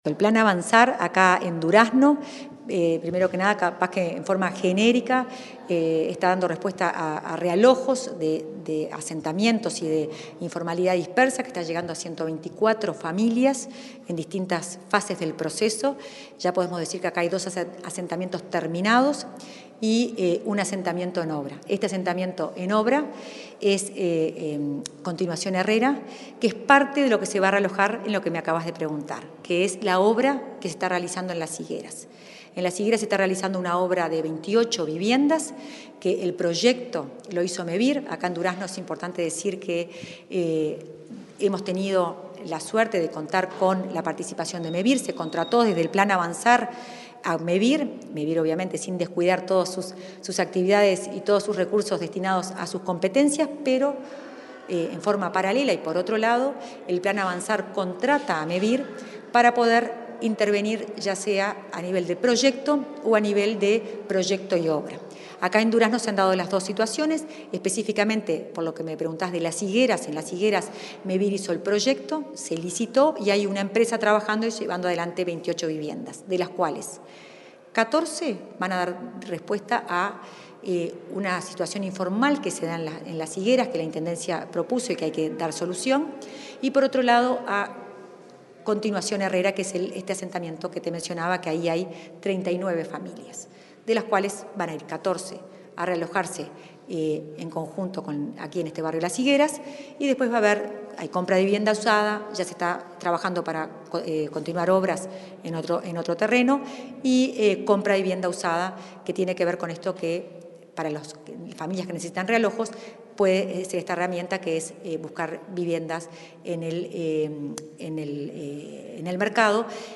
Entrevista a la directora de Integración Social y Urbana del Ministerio de Vivienda, Florencia Arbeleche